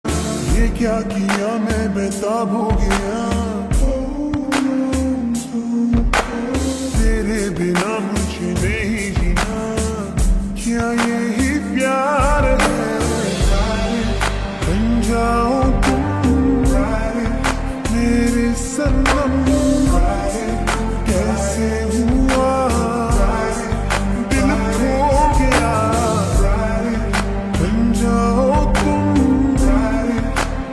This is a famous Instagram Reel Song in Slow Version.